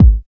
51 KICK.wav